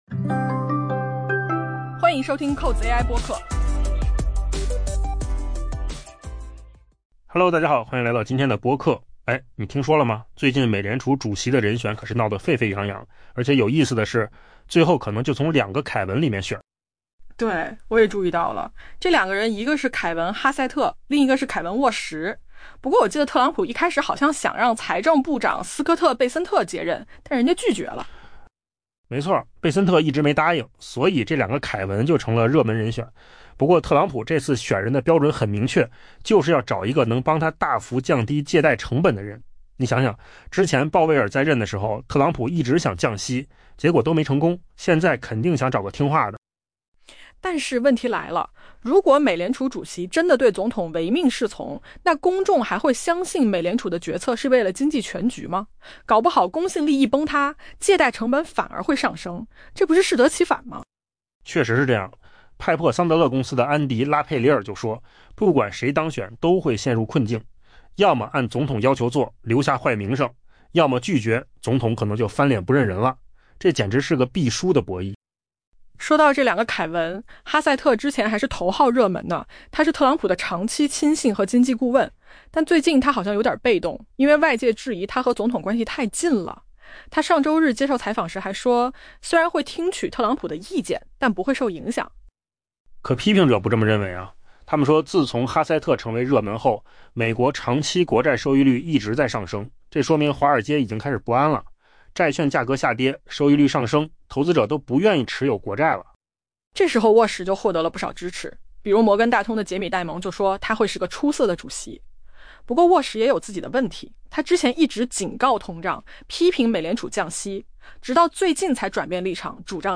AI 播客：换个方式听新闻 下载 mp3 音频由扣子空间生成 美联储下任主席的人选注定会在两位名叫凯文的人当中产生。